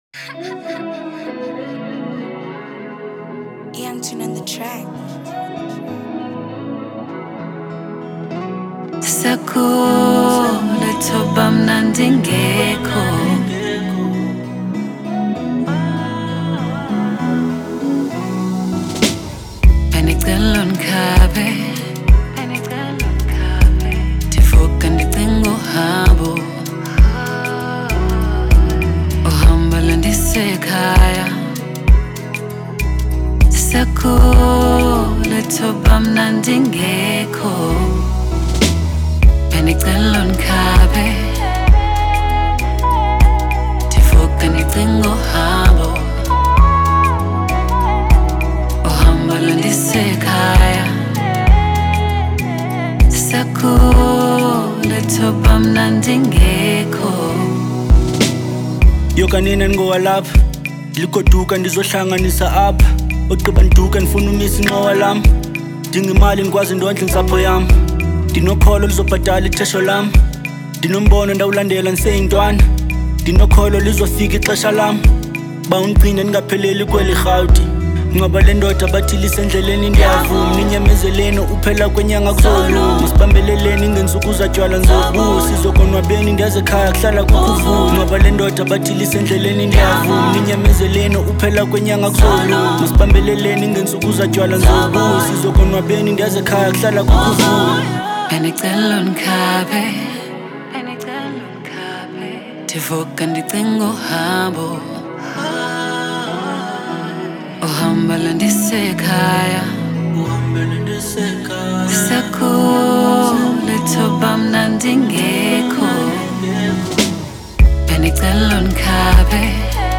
a super talented rapper and music star from South Africa